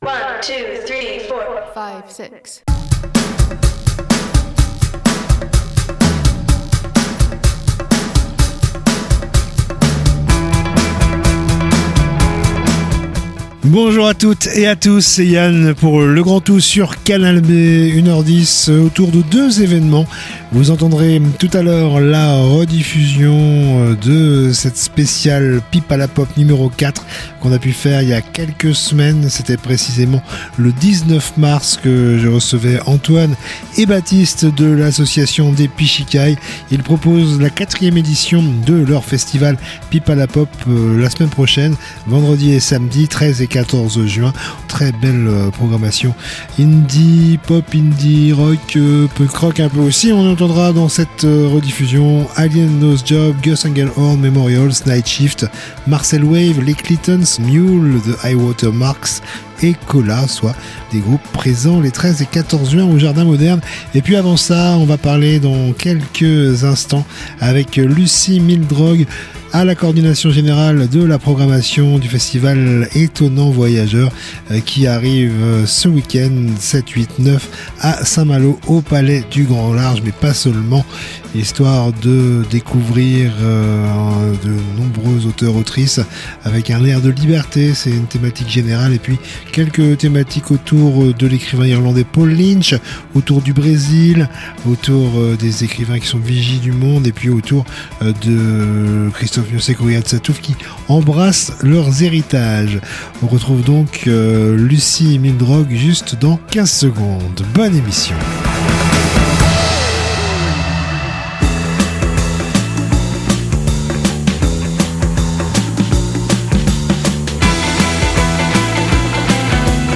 itv musique + infos-concerts